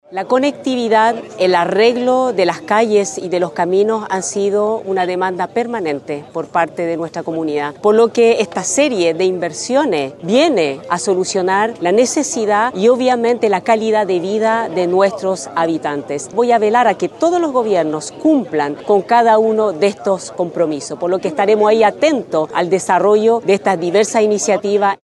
Ante ello, la alcaldesa, Elizabeth Arévalo, aseguró que la conectividad ha sido una demanda histórica por parte de la comunidad de Rapa Nui.
cu-inicio-pavimentacion-rapa-nui-alcaldesa.mp3